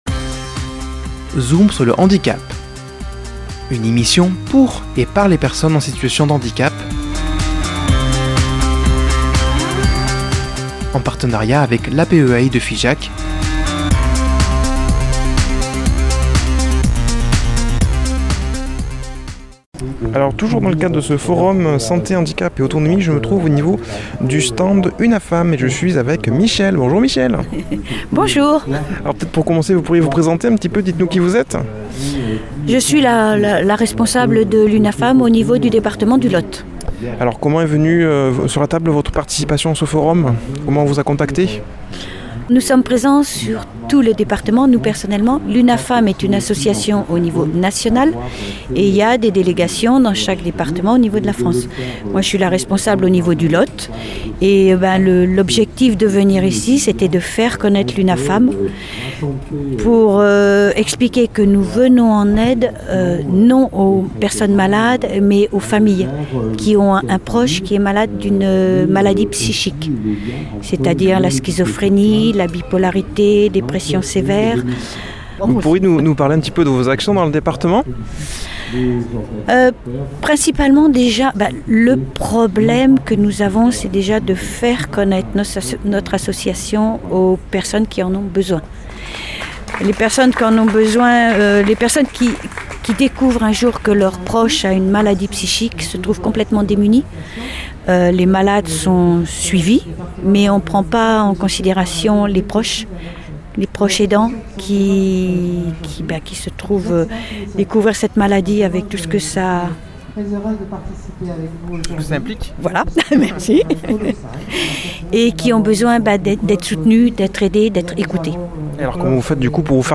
Un reportage en plusieurs parties tout au long de cette saison radiophonique. Aujourd’hui épisode 7 avec le stand de l’UNAFAM